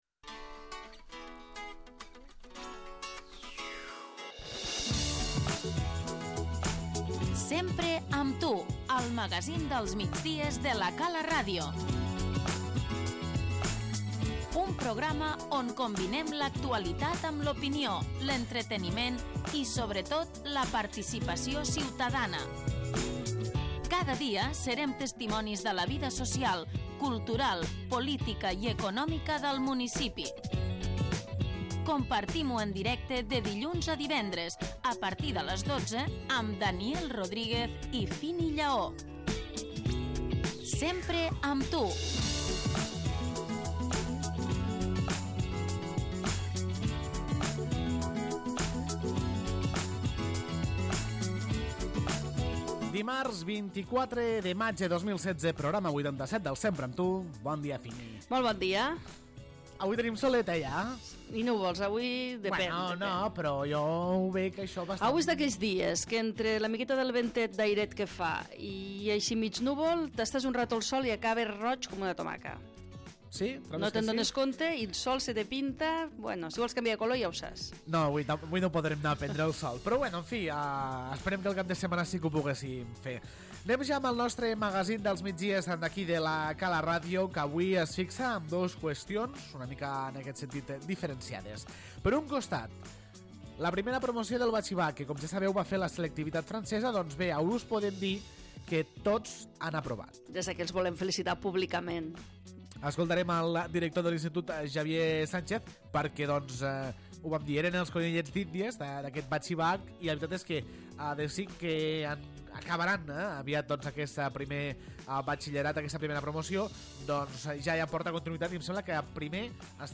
L'ENTREVISTA Aquest diumenge les Dones Endavant sortiran al carrer per mostrar els treballs que realitzen de labors com el patchwork, el boixet, la pintura i diverses manualitats.